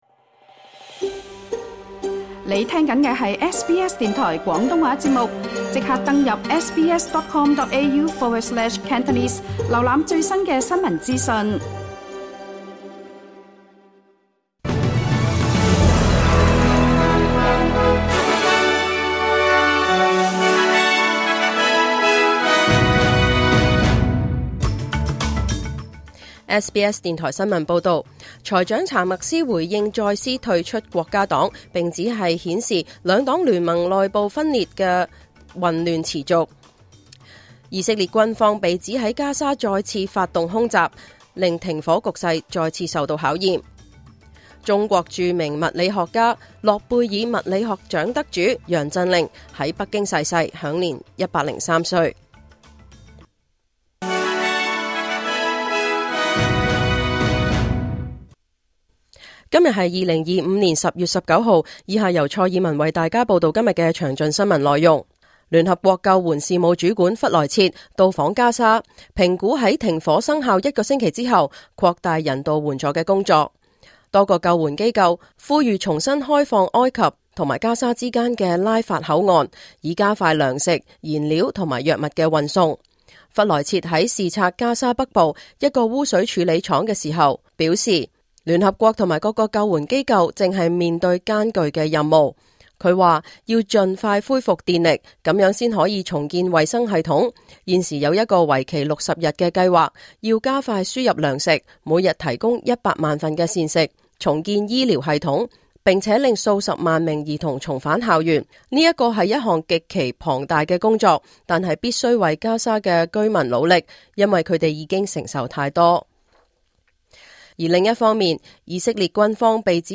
2025 年 10 月 19 日 SBS 廣東話節目詳盡早晨新聞報道。